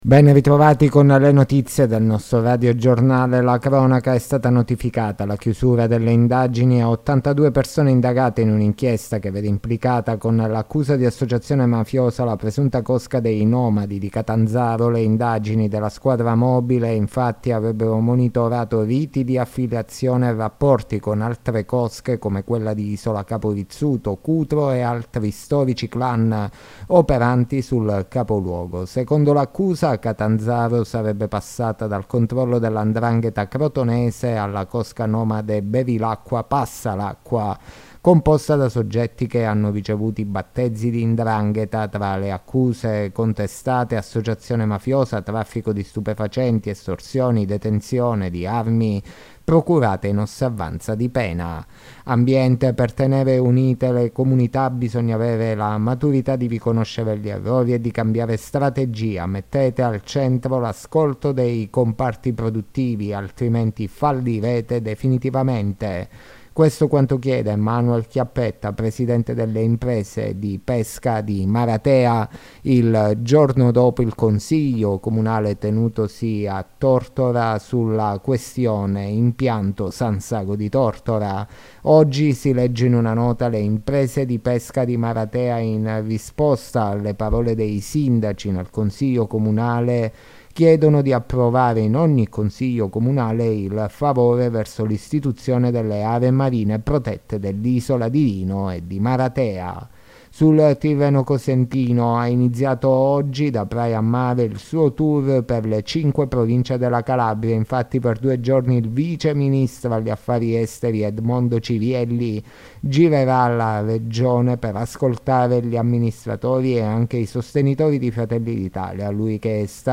Le notizie della sera di Giovedì 26 Ottobre 2023